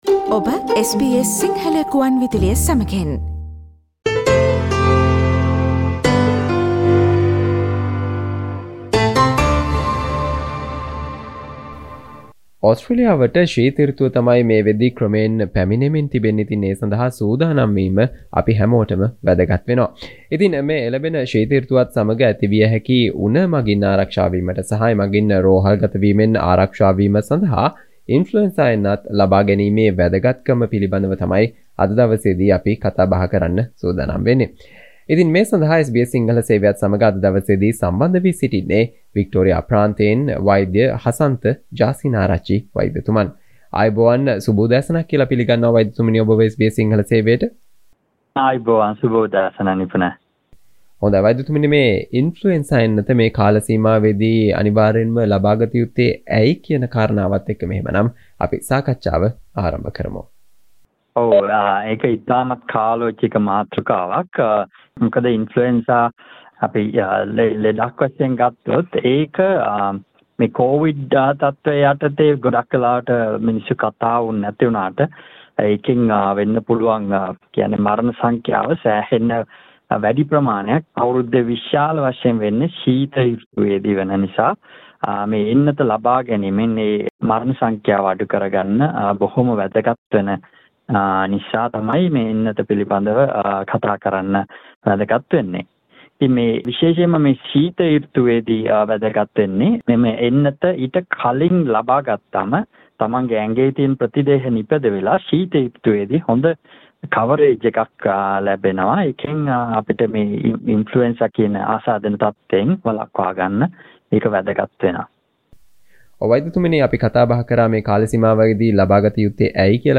එළඹෙන ශීත සෘතුවත් සමග වැළඳිය හැකි උණ මගින් ආරක්ෂා වීමට සහ එමගින් රෝහල්ගත වීමෙන් ආරක්ෂා වීම සඳහා "ඉන්ෆ්ලුවෙන්සා එන්නත්" ලබාගැනීමේ වැදගත්කම සම්බන්ධයෙන් SBS සිංහල සේවය සිදු කල සාකච්චාවට සවන් දෙන්න